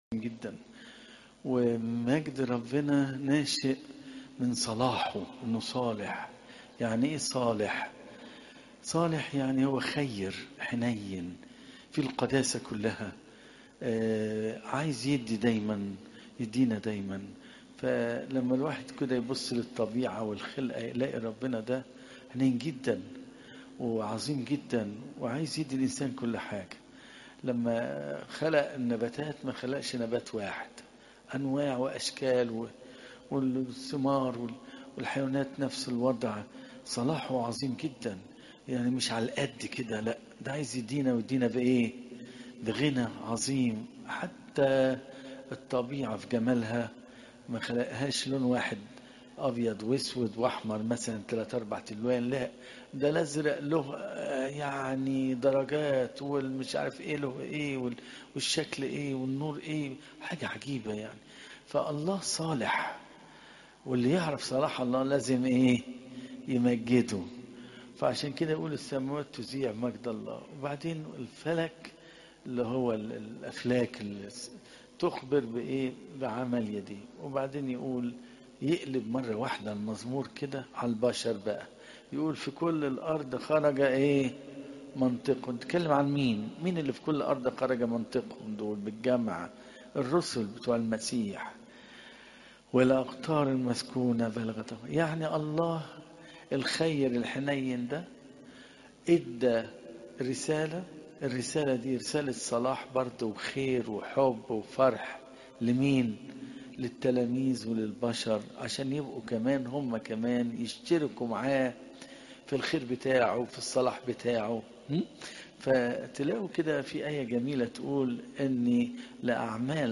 عظات قداسات الكنيسة صوم الميلاد (مت 10 : 1 - 15)